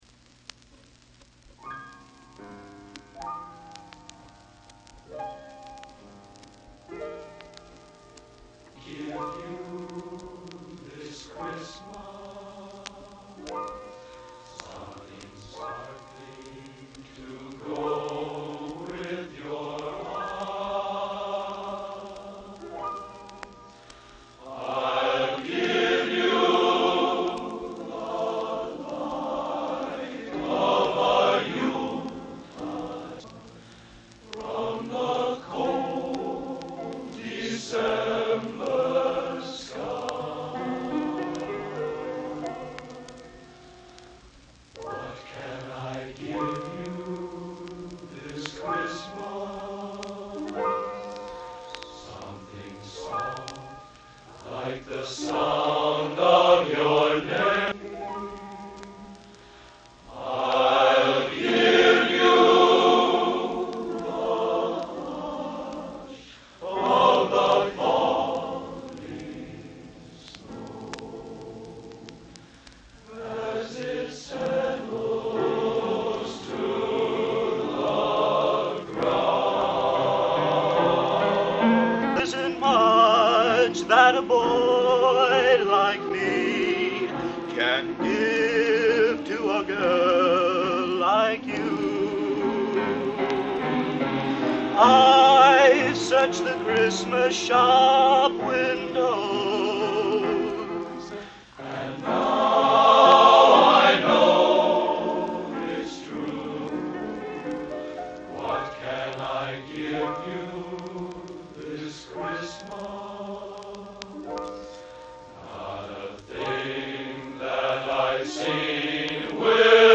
Genre: Holiday | Type: Christmas Show